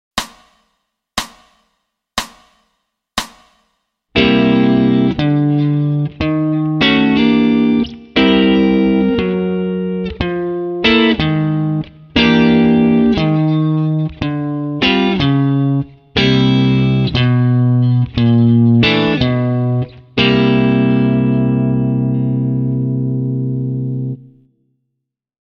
This second example focuses on creating walking basslines using chord shapes stemming from the 5th string, to outline an overall “C7” sound.
C7WalkPlus4thsVoicings(SLOW.mp3